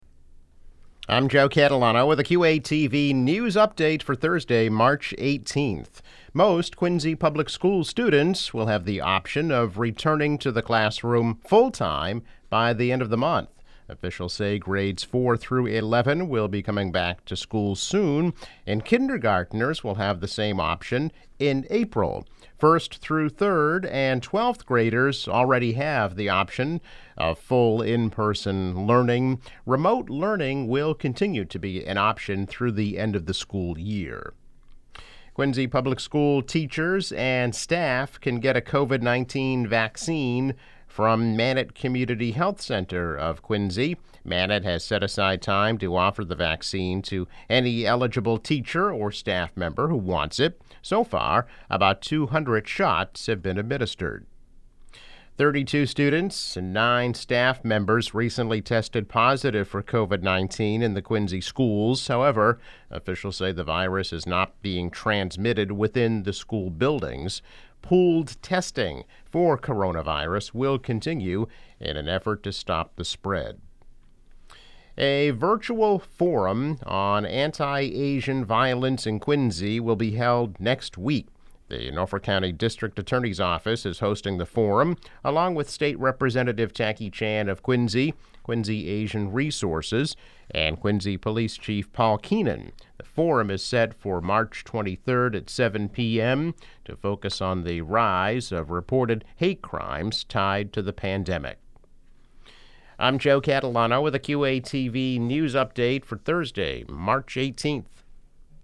News Update - March 18, 2021